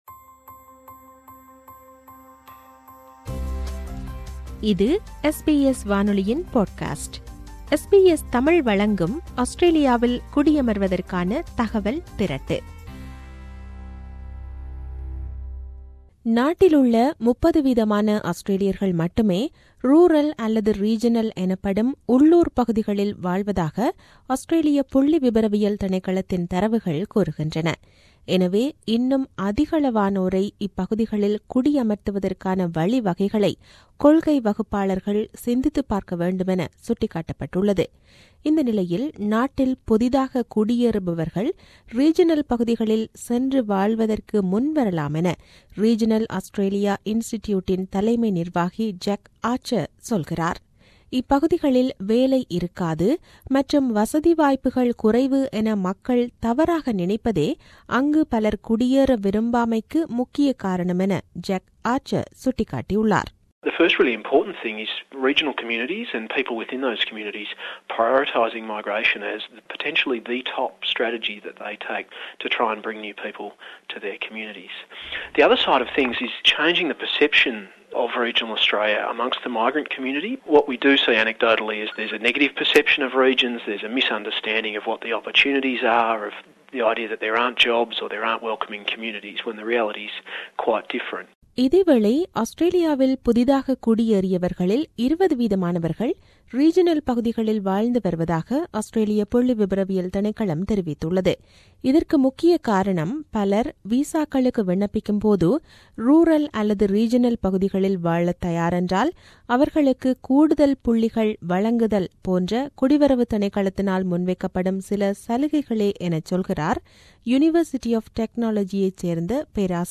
செய்தி விவரணத்தை தமிழில் வழங்குகிறார்